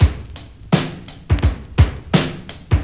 • 84 Bpm 00's Drum Groove E Key.wav
Free breakbeat - kick tuned to the E note. Loudest frequency: 1104Hz
84-bpm-00s-drum-groove-e-key-Nei.wav